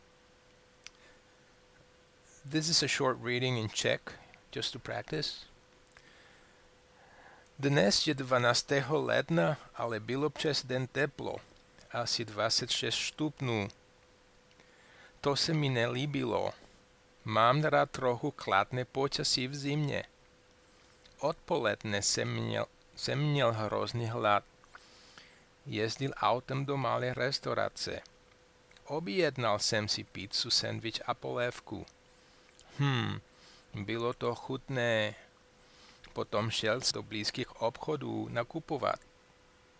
Czech reading weather